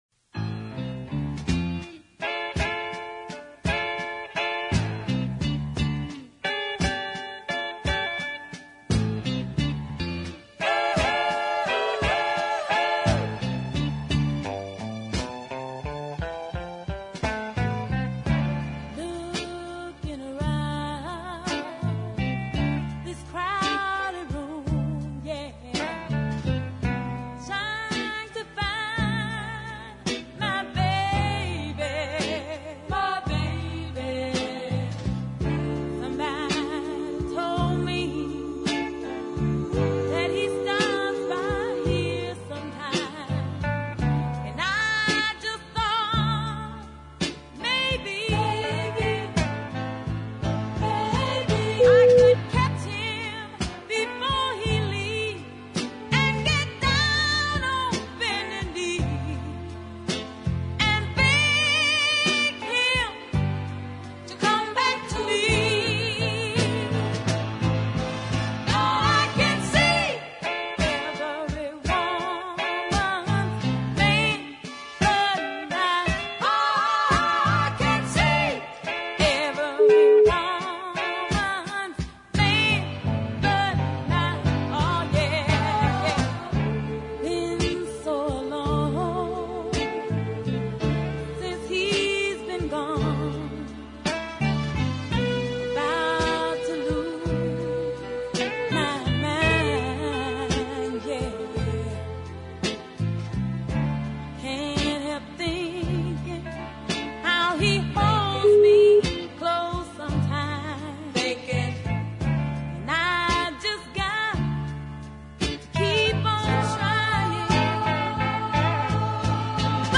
the quite superb bluesy deep soul